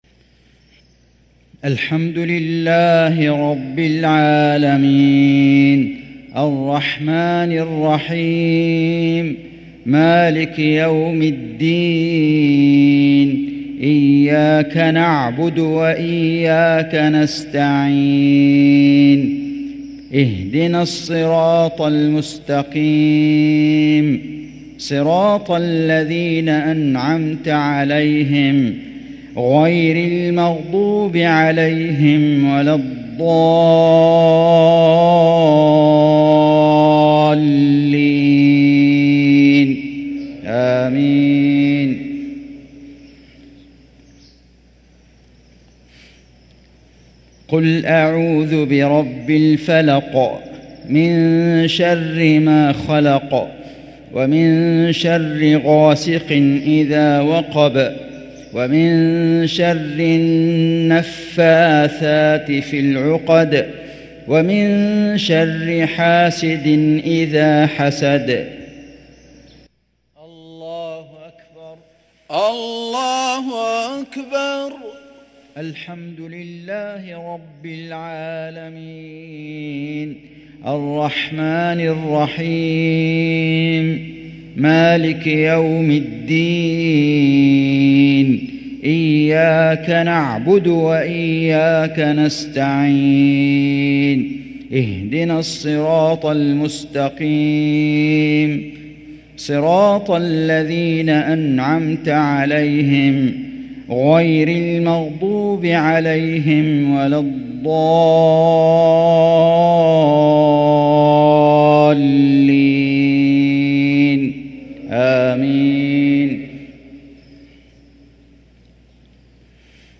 صلاة المغرب للقارئ فيصل غزاوي 22 شعبان 1444 هـ
تِلَاوَات الْحَرَمَيْن .